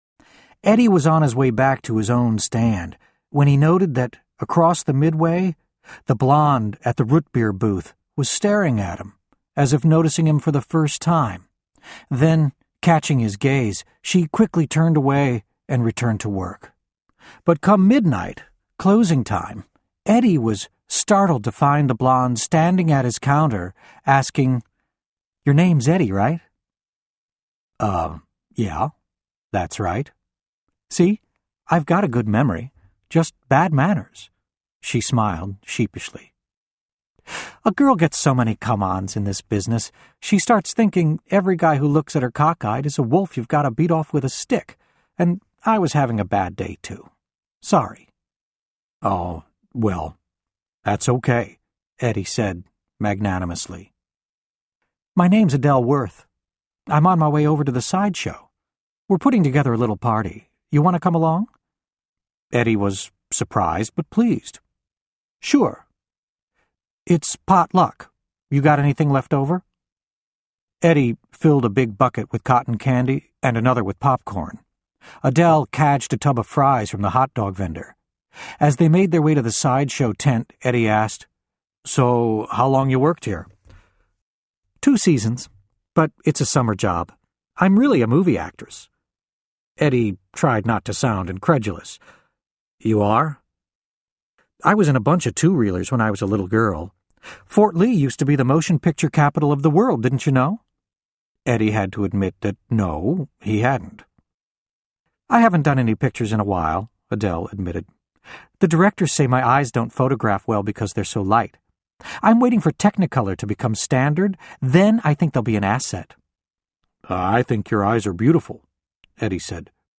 voiceover : audio books